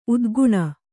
♪ udguṇa